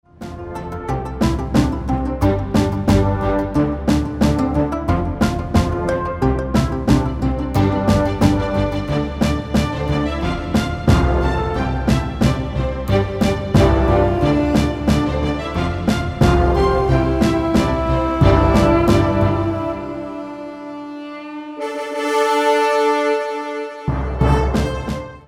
Muzyka o charakterze muzyki filmowej.